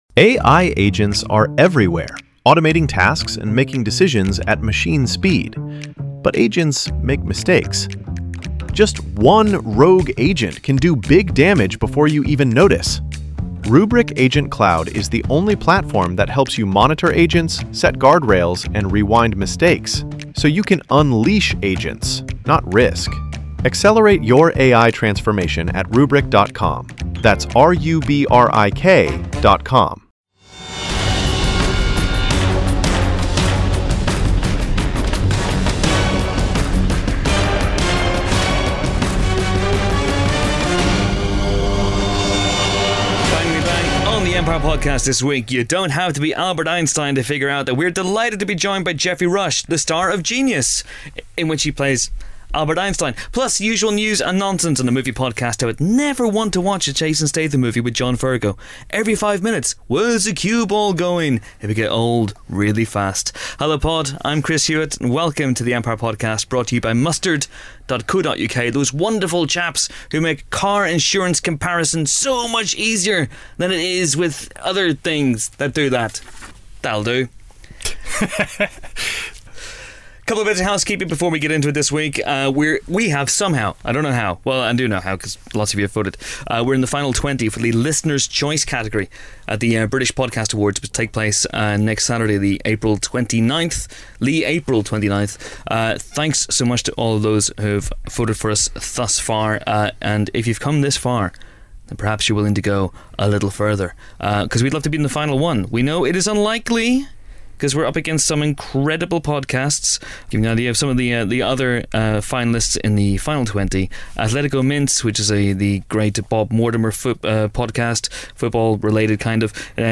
#259: Geoffrey Rush The Empire Film Podcast Bauer Media Tv & Film, Film Reviews 4.6 • 2.7K Ratings 🗓 21 April 2017 ⏱ 80 minutes 🔗 Recording | iTunes | RSS 🧾 Download transcript Summary This week, the genius actor (and Genius actor) Geoffrey Rush pops by the pod booth. Plus, the team discuss the movie scenes that consistently make them laugh.